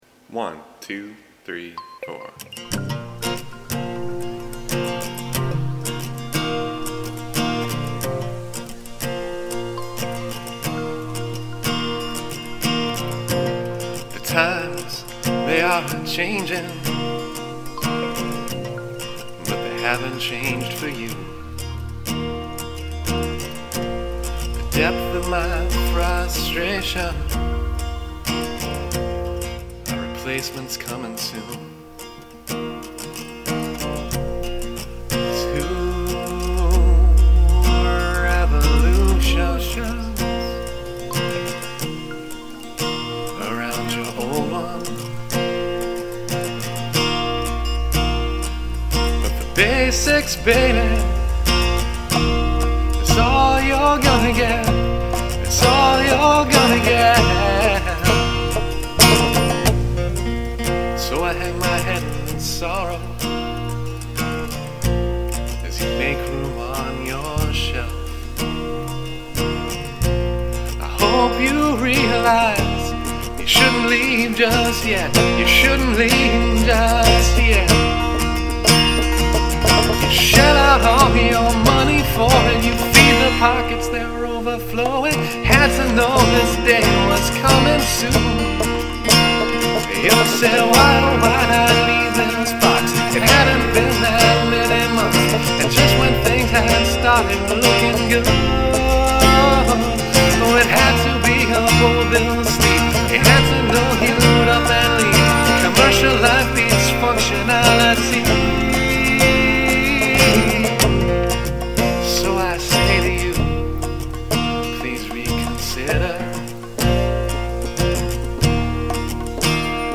/humorous original compositions/
guitar and vocals